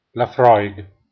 La pronuncia di Laphroaig
La corretta pronuncia è infatti la-FROIG.